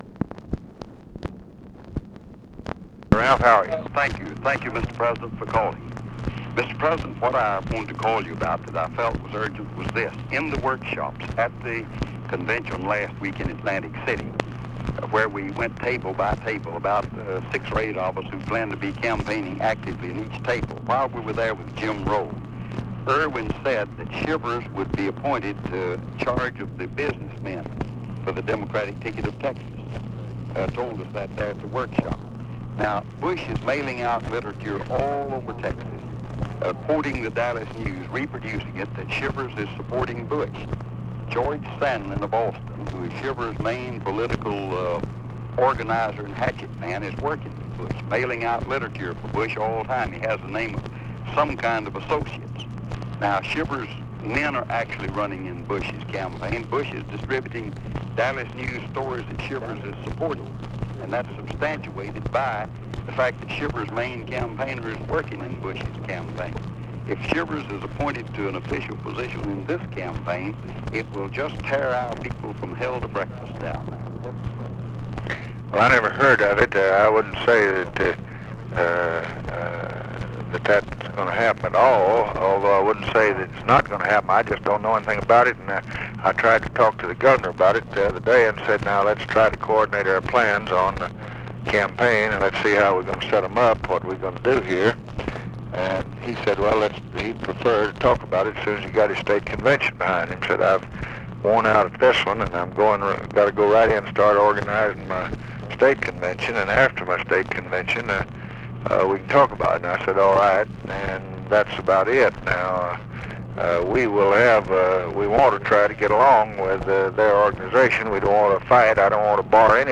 Conversation with RALPH YARBOROUGH, August 31, 1964
Secret White House Tapes